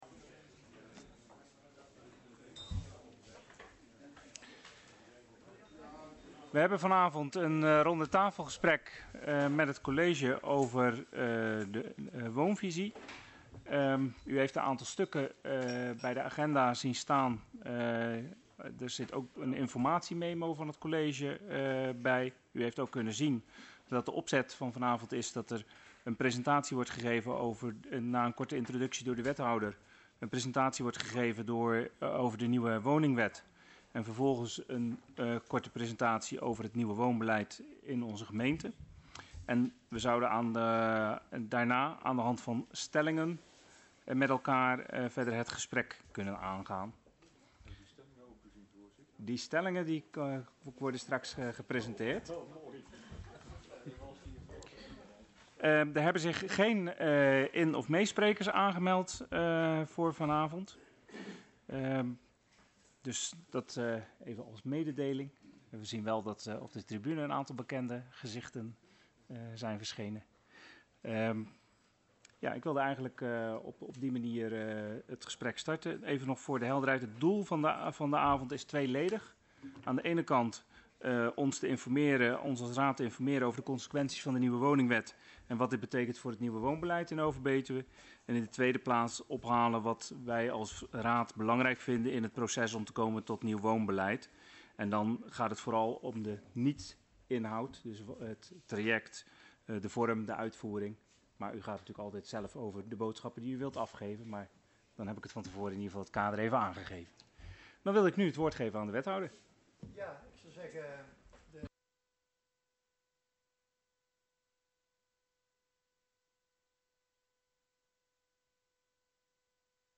Locatie Hal, gemeentehuis Elst Voorzitter dhr. A. Noordermeer Toelichting RTG actualisatie woonvisie (en daarnaast nog uitleg nieuwe woningwet) Agenda documenten 15-10-20 Opname hal inzake RTG actualisatie woonvisie en toelichting nieuwe woningwet.MP3 30 MB